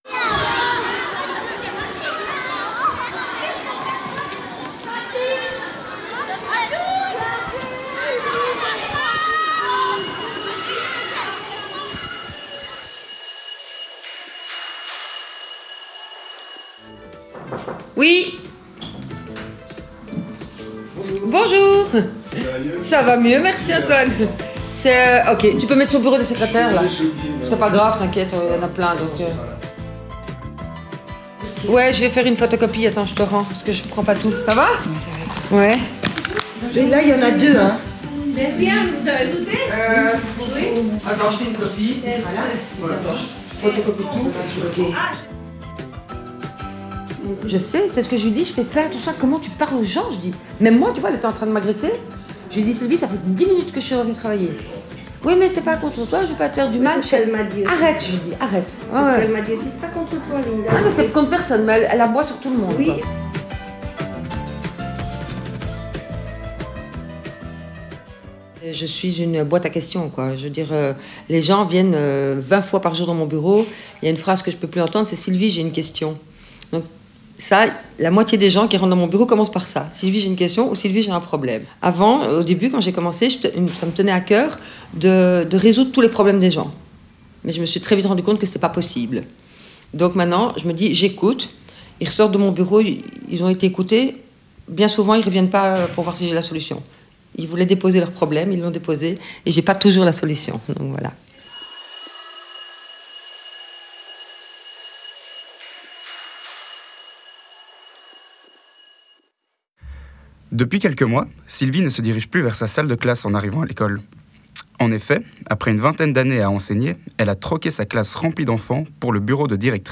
Accueil > Documentaires > Une nouvelle directrice est arrivée